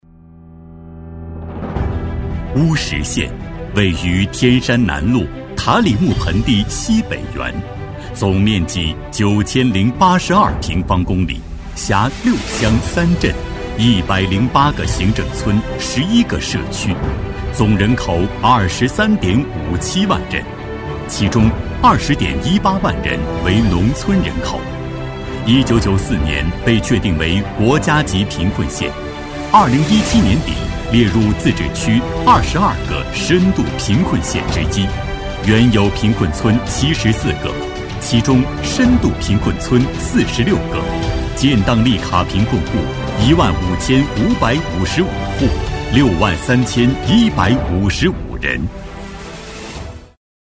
男14-政府汇报《乌什县 六大产业》-大气、雄浑、力度
男14-透亮故事感 大气浑厚
男14-政府汇报《乌什县 六大产业》-大气、雄浑、力度.mp3